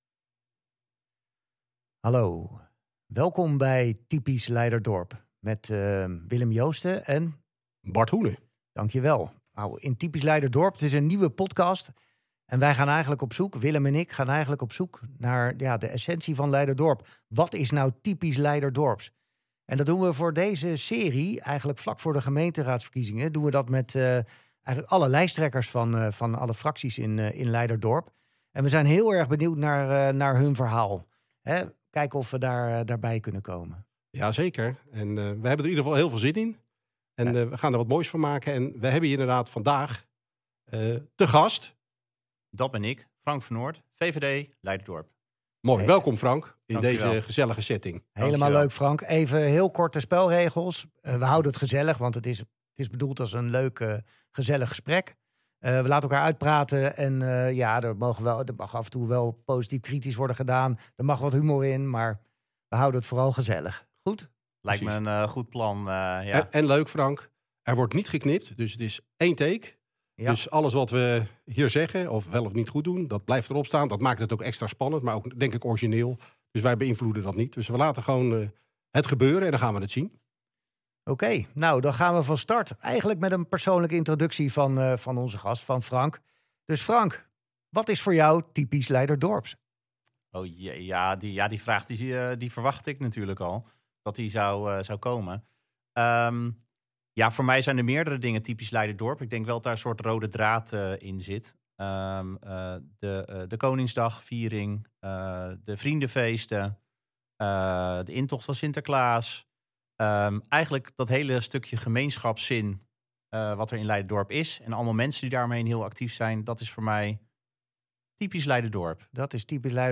Typisch Leiderdorp is een podcast van twee opgewekte, ondernemende Leiderdorpers met hart voor het dorp.